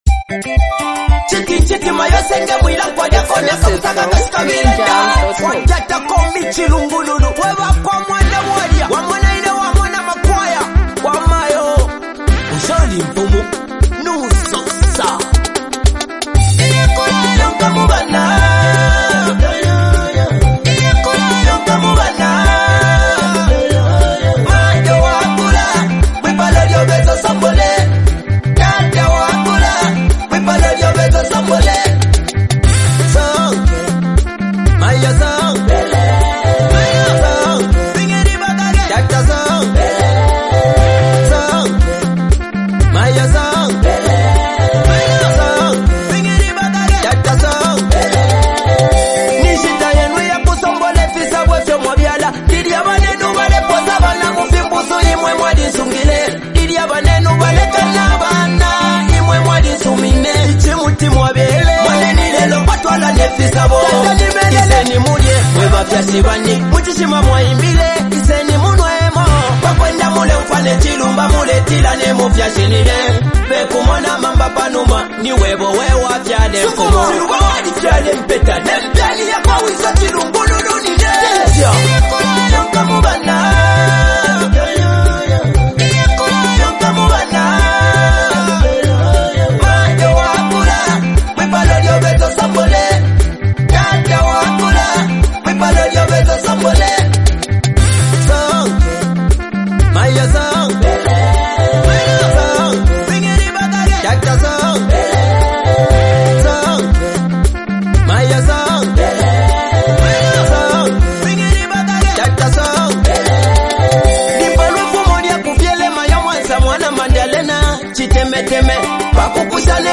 an emotionally charged song
a motivational and socially conscious track